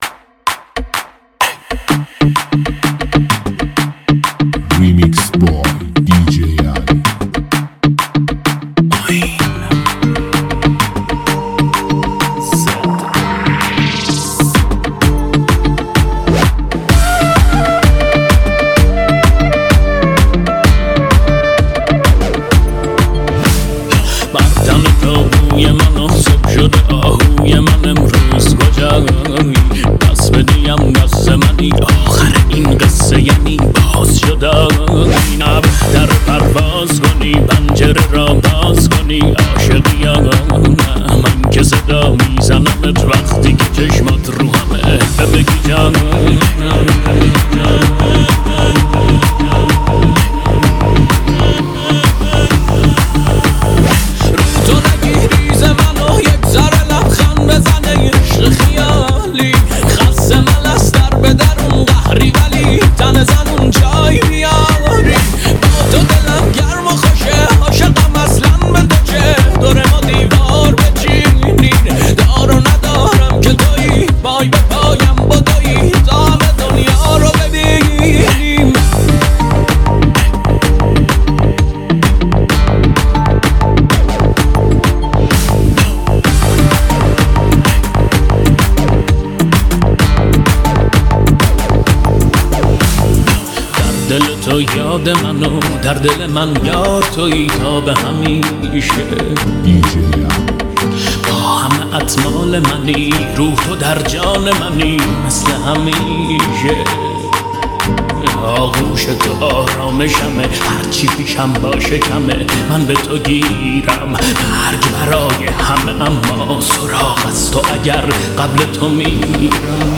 ریمیکس پرانرژی و شنیدنی
ملودی گیرا